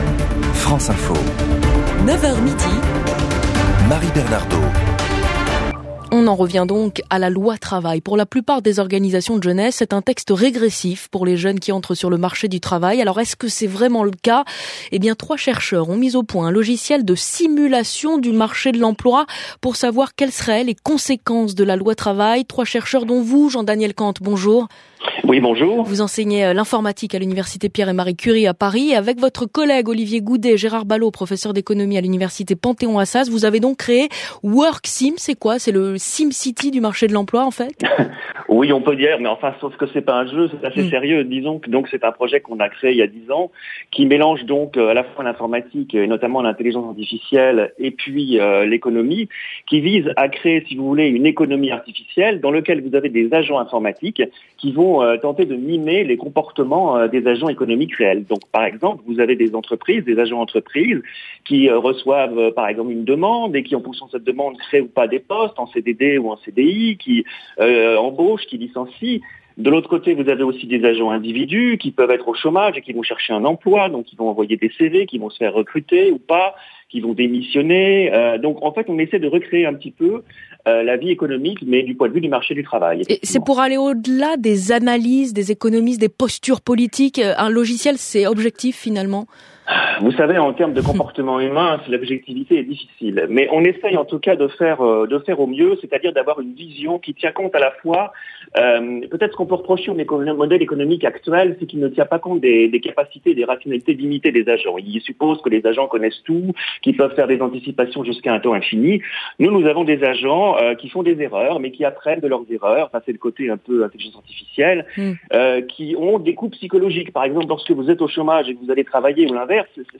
Radio
France Info - 11 avril 2016 - 9h15 : Interview on French National Radio(audio)